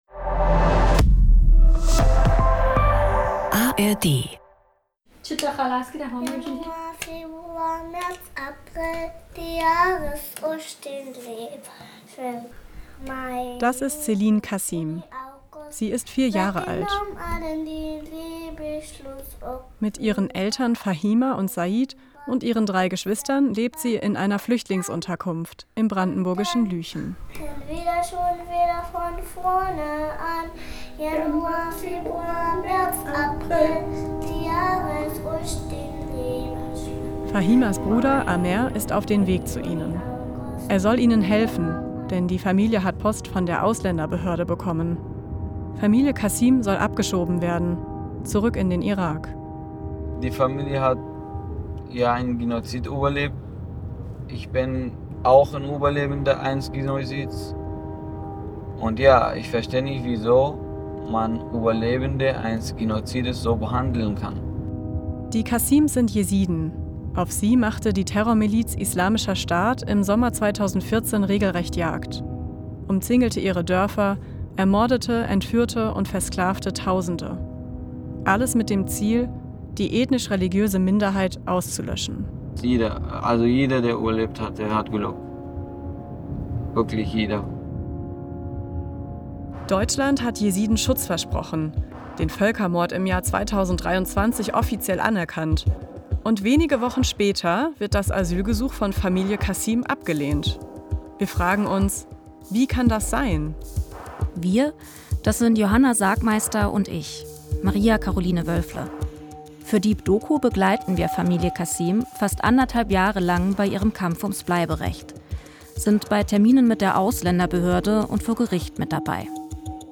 Wer bin ich ohne Job? Wenn wir Angst vor Bedeutungslosigkeit haben – Deep Doku – Podcast